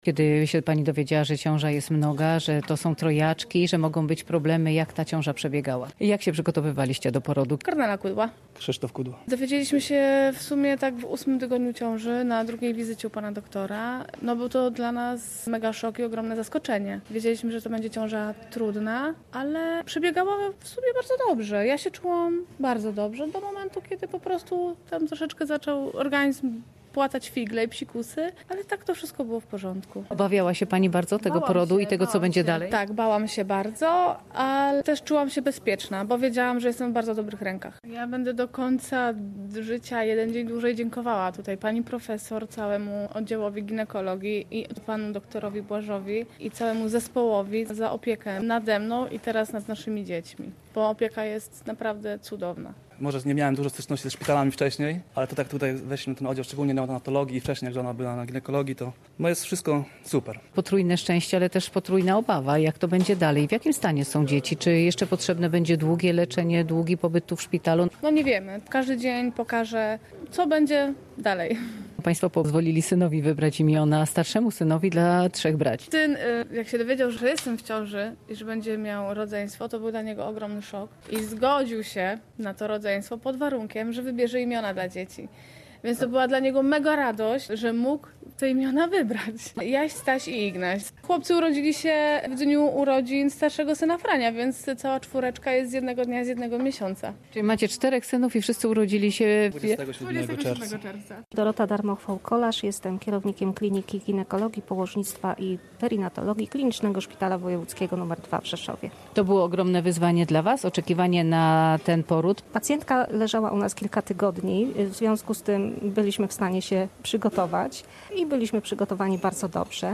Z lekarzami prowadzącymi ciążę, a teraz opiekującymi się maluszkami oraz rodzicami rozmawiała w szpitalu